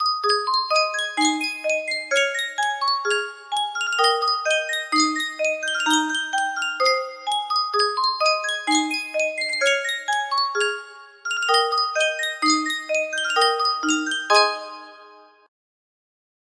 Sankyo Music Box - Wabash Cannonball BBR music box melody
Full range 60